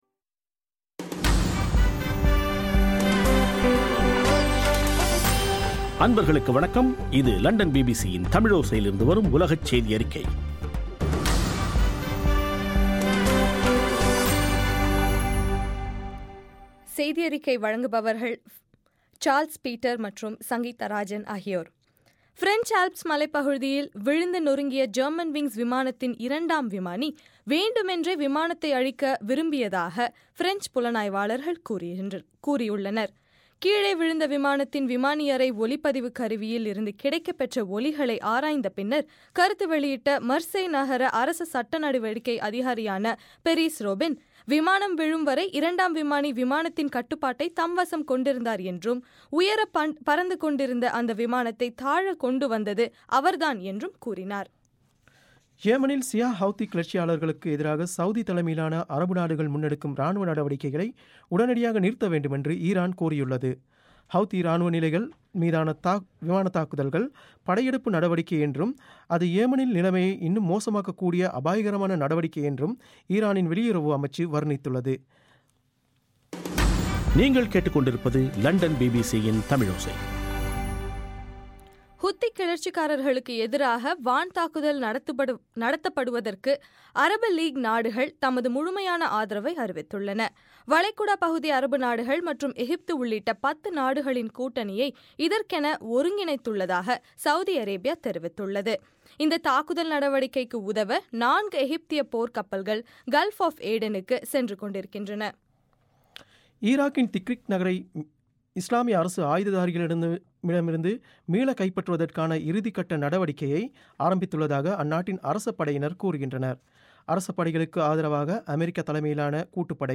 இன்றைய ( மார்ச் 26) பிபிசி தமிழோசை செய்தியறிக்கை